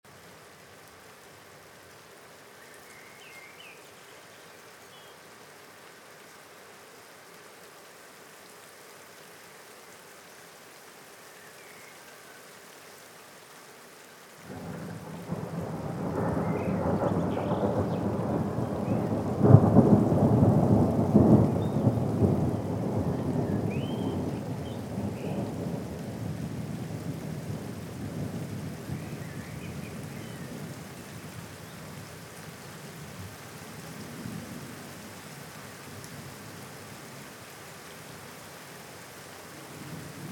صدای طوفان و باران در جنگل همراه صدای پرنده: